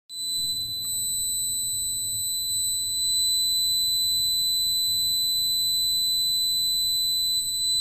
3. Alarma de oficina
Alarma-oficinas-3.mp3